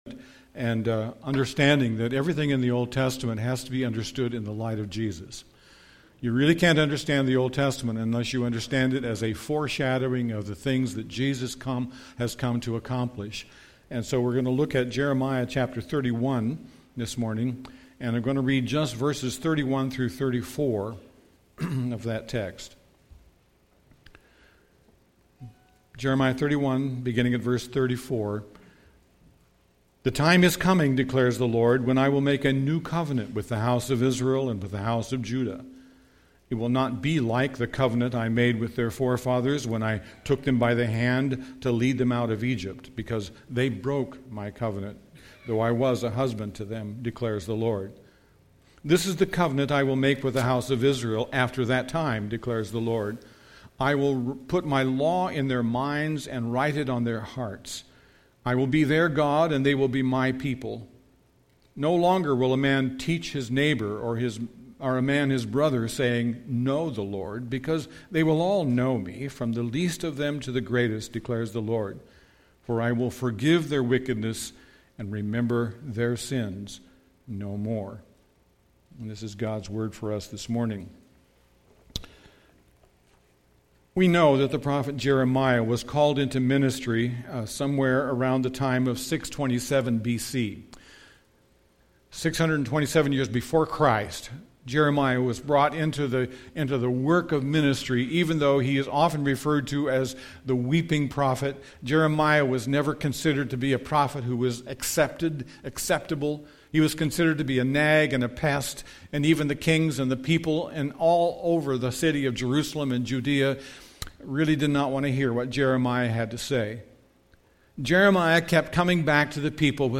Jeremiah 31:31-34 Service Type: Sunday AM Bible Text